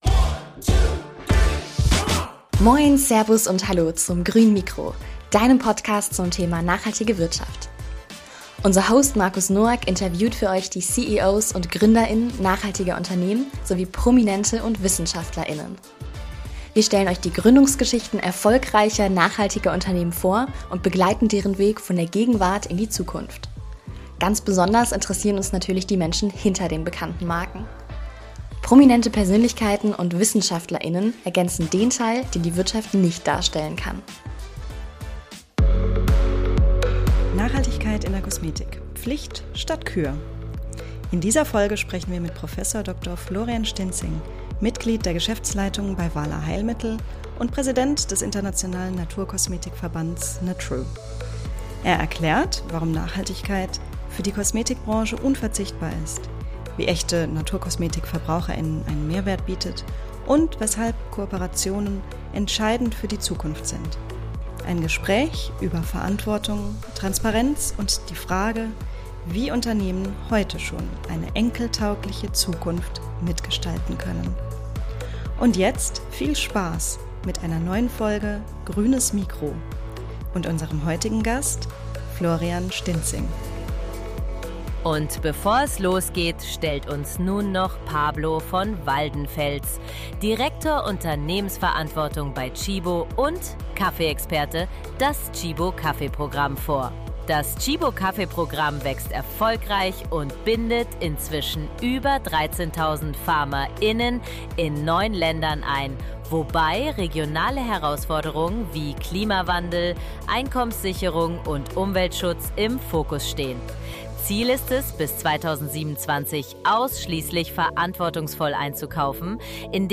Im Interview spricht er über die Kraft der Pflanzen, Forschung und Innovation in der Naturkosmetik, nachhaltiges Wirtschaften und warum echte Transparenz für die Zukunft unverzichtbar ist.